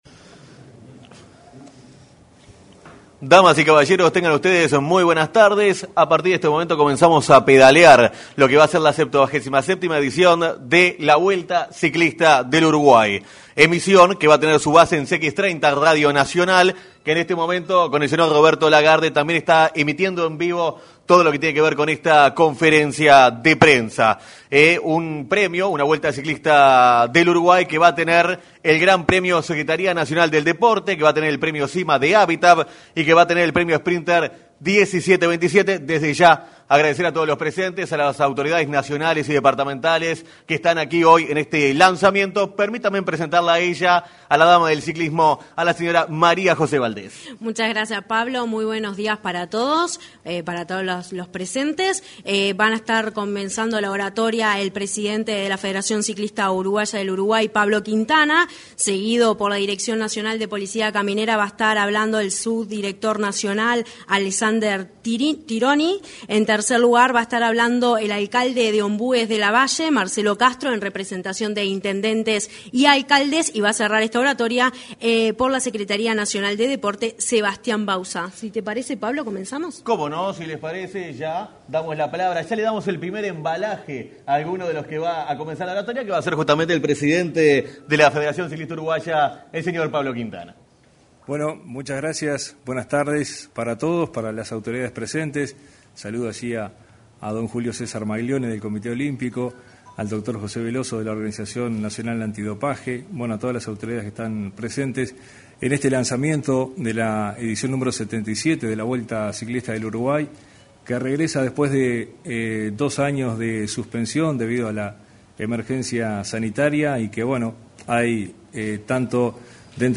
En el salón de actos de Torre Ejecutiva, se concretó el lanzamiento de la 77.ª Vuelta Ciclista de Uruguay.